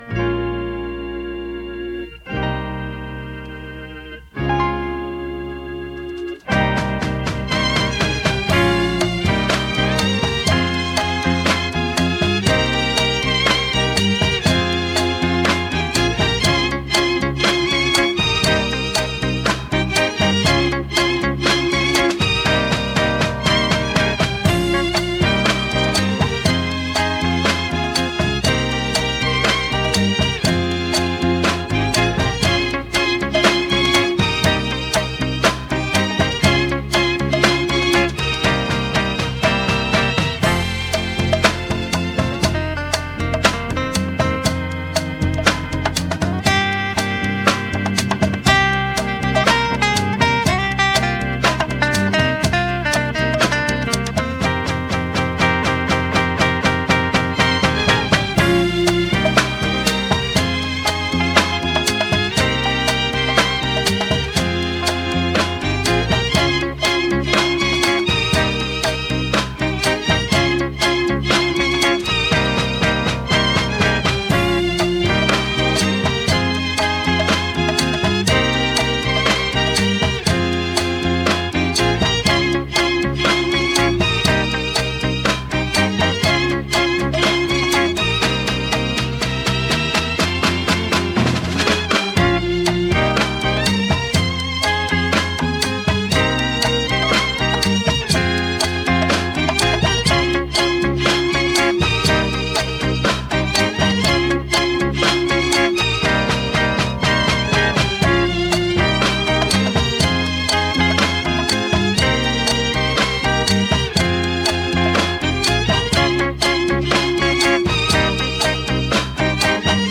infectious rhythms that defined an era.
Mp3 demos of a few below.(Wav files will be better quality)